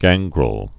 (găngrəl)